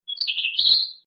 Bird Chirping 04
Bird_chirping_04.mp3